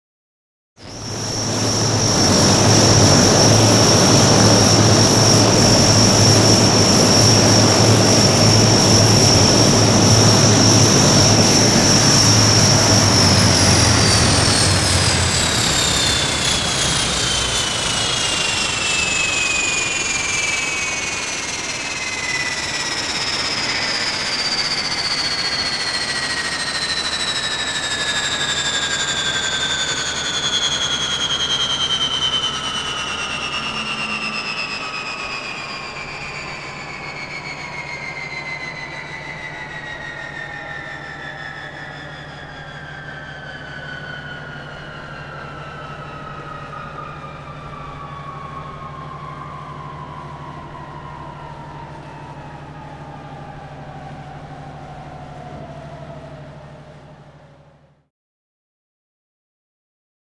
Prop Plane; Cut Engine; Exterior Hercules Engines Cut And Wind Down.